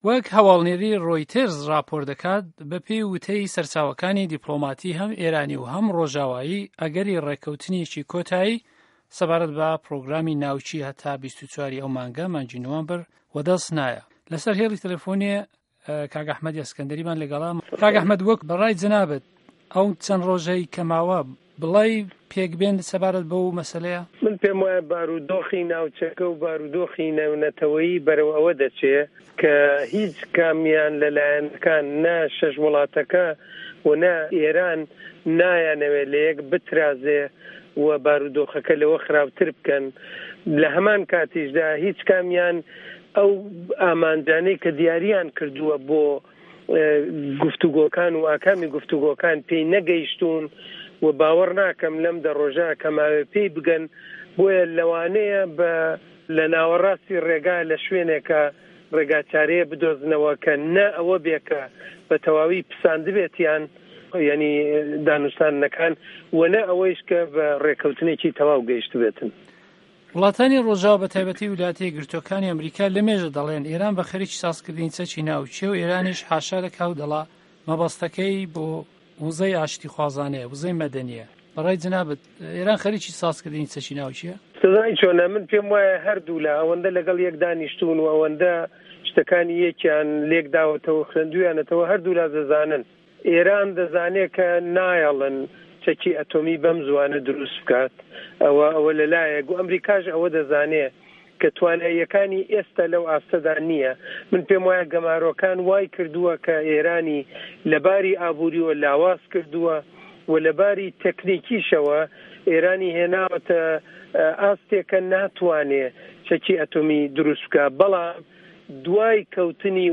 له‌ وتووێژێکی له‌ گه‌ڵ ده‌نگی ئه‌مه‌ریکا دا